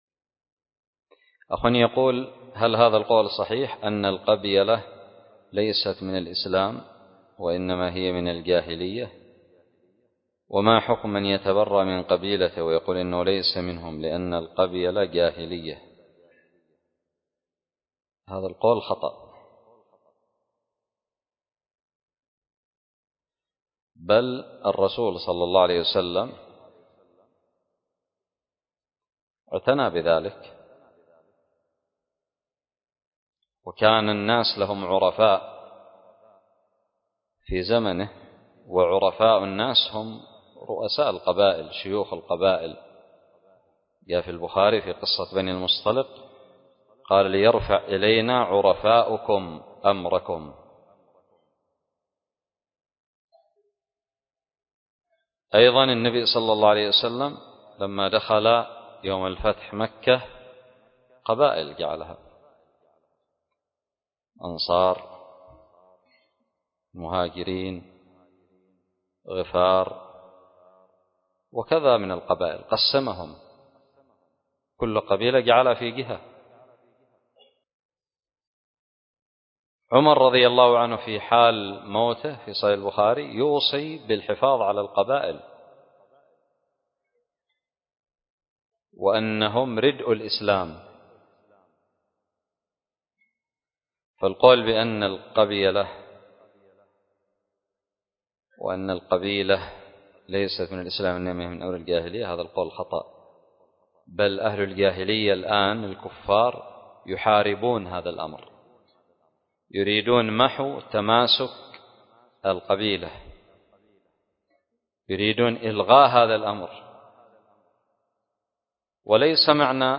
:العنوان فتاوى عامة :التصنيف 1447-4-9 :تاريخ النشر 35 :عدد الزيارات البحث المؤلفات المقالات الفوائد الصوتيات الفتاوى الدروس الرئيسية هل هذا القول صحيح أن القبيلة ليست من الإسلام؟ سؤال قدم لفضيلة الشيخ حفظه الله